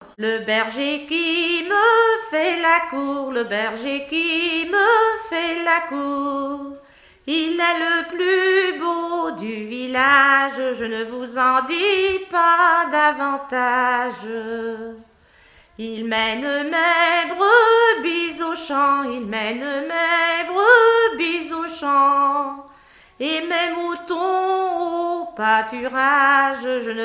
Genre strophique
Témoignages sur la pêche, accordéon, et chansons traditionnelles
Pièce musicale inédite